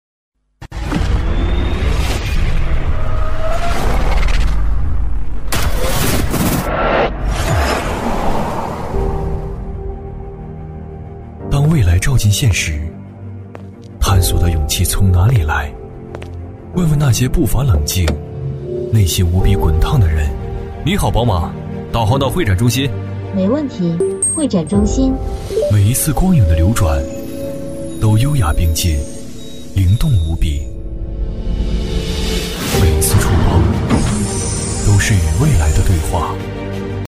男C16-TVC广告 - 宝马5系
男C16-年轻质感 年轻稳重
男C16-TVC广告 - 宝马5系.mp3